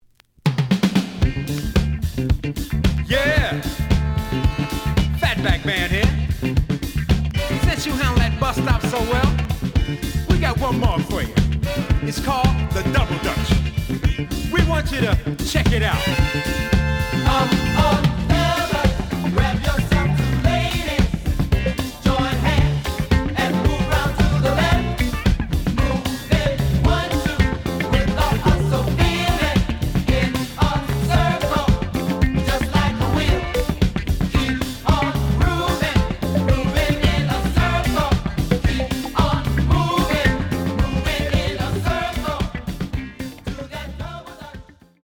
The audio sample is recorded from the actual item.
●Genre: Funk, 70's Funk
Some click noise on B side due to scratches.